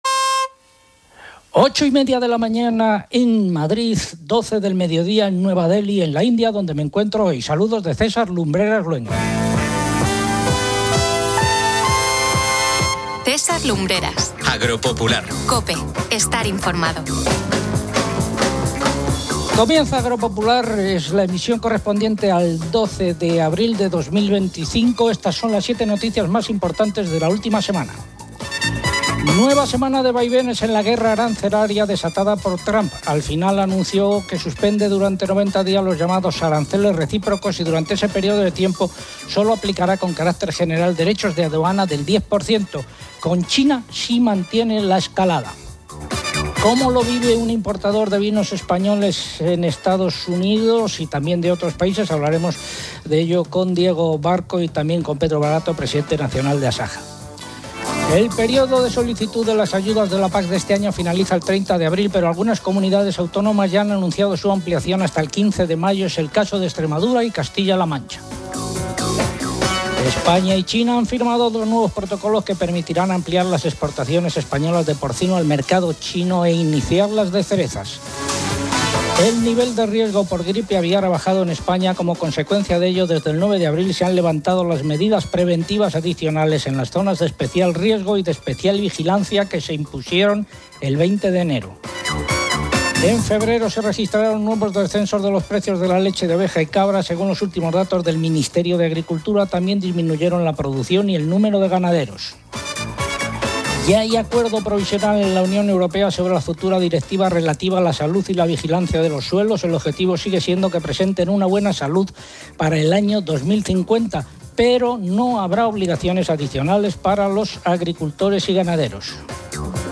Un programa de radio que da voz al campo, escucha las principales reivindicaciones y lucha por dotar a este sector de las fortalezas que necesita. Información, entrevistas y reportajes sobre el sector terciario.